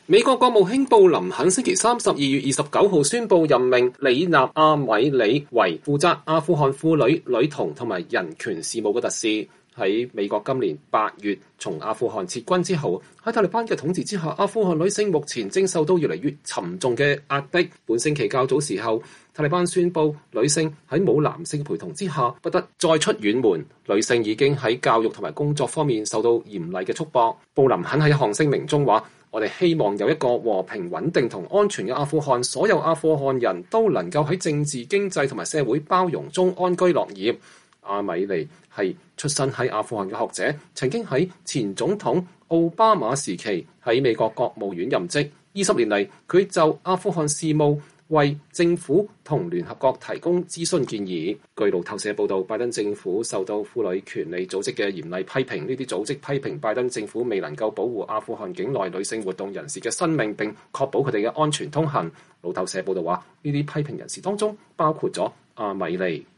阿米里接受美國之音視頻採訪。